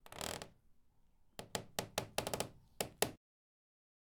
Creek.ogg